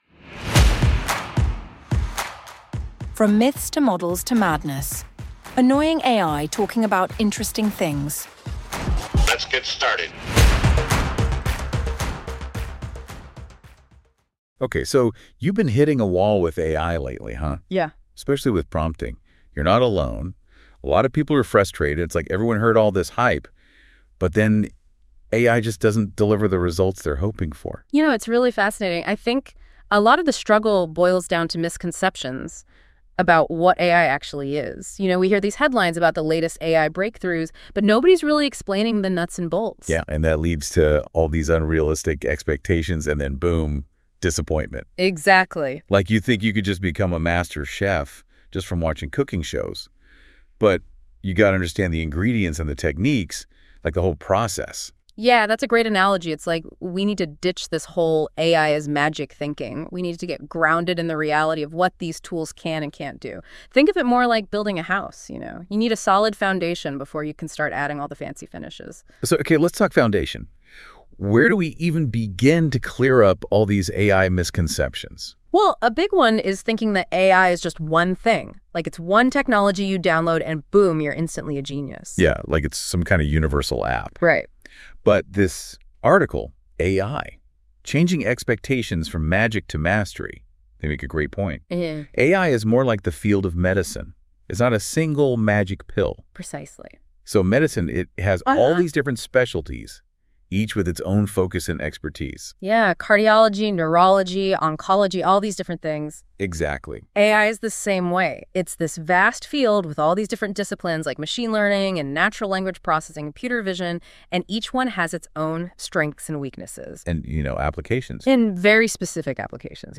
From Myths To Models To Madness: Annoying AI Talking about Interesting Things